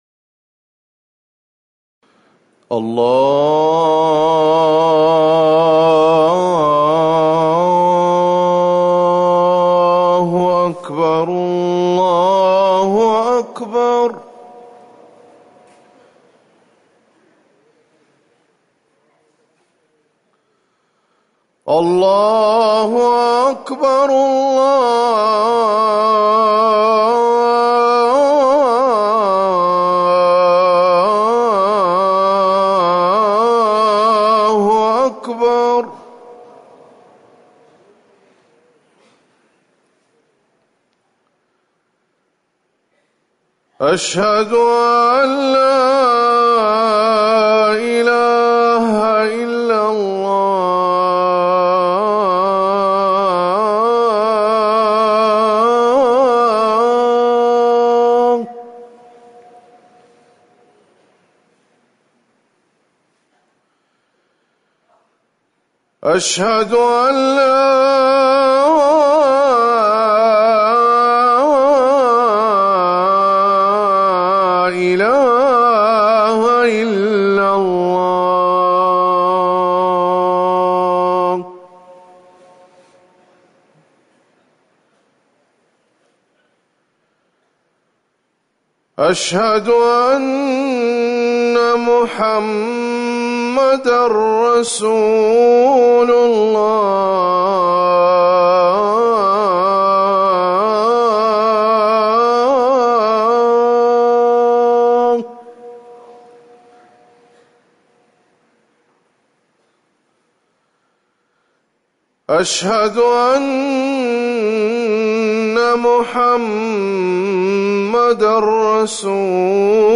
أذان الفجر الأول - الموقع الرسمي لرئاسة الشؤون الدينية بالمسجد النبوي والمسجد الحرام
تاريخ النشر ٢٤ صفر ١٤٤١ هـ المكان: المسجد النبوي الشيخ